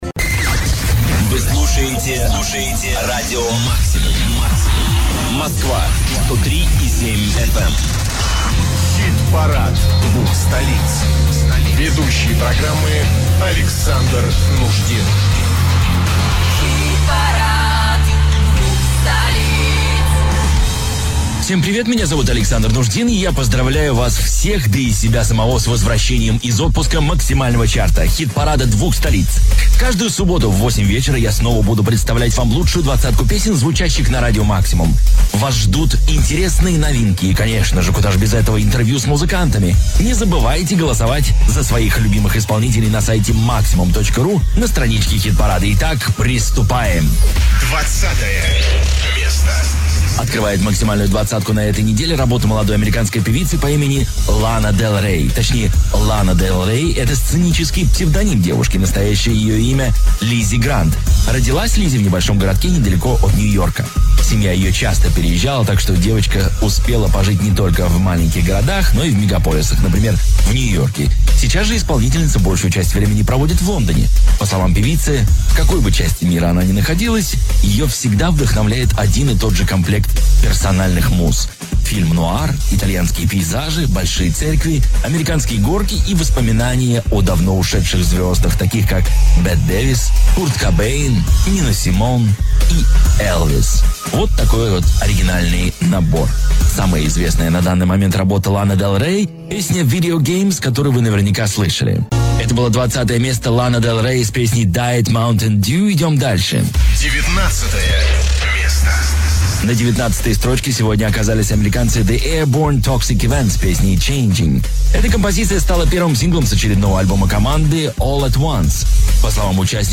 Возвращения Хит Парада Двух Столиц после отпуска на "Максимум". Запись эфира.